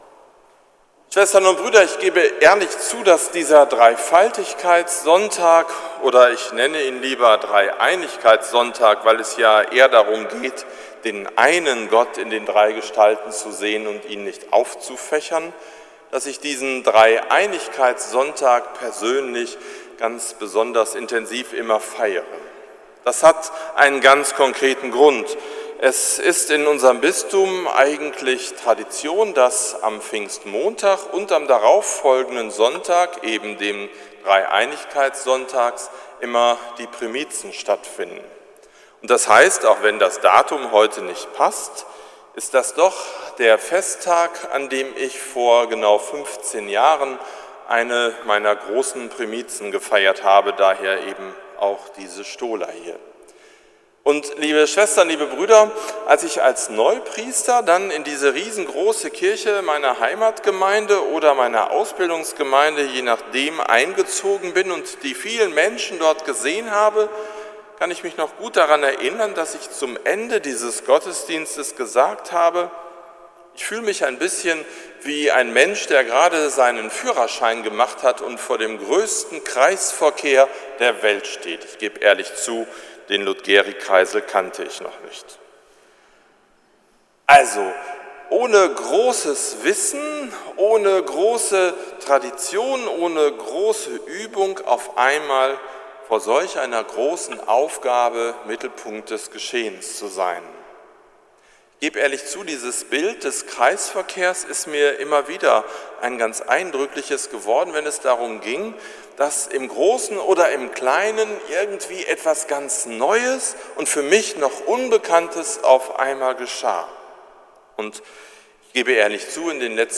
Predigt zum Dreieinigkeitssonntag 2018 – St. Nikolaus Münster
predigt-zum-dreieinigkeitssonntag-2018